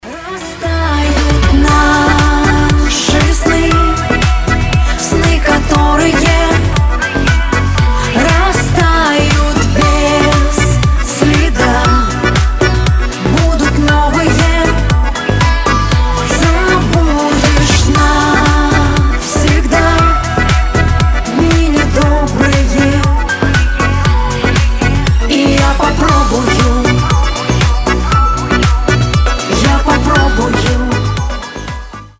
• Качество: 320, Stereo
грустные
женский голос
пианино